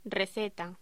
Locución: Receta
voz
Sonidos: Voz humana